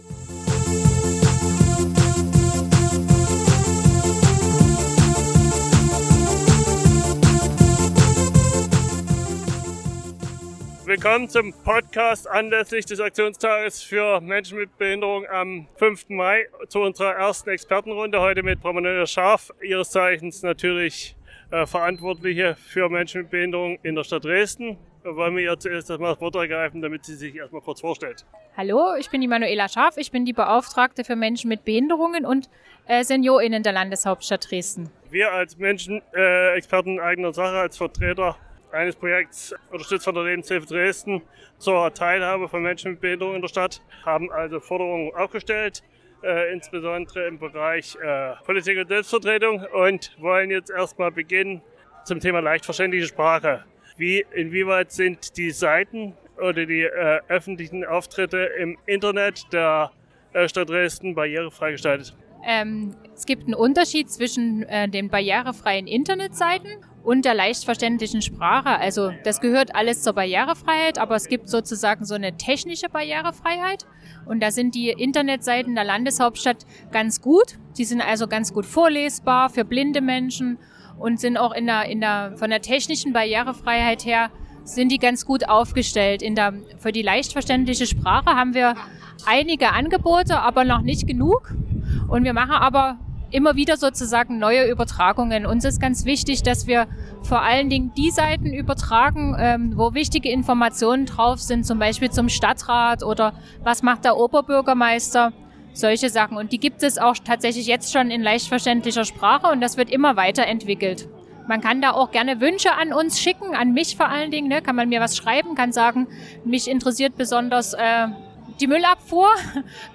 Expertengespräch mit Manuela Scharf - Beauftragte für Menschen mit Behinderungen und Senior/innen ~ reden; nur mit uns! Podcast